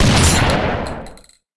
rifle.ogg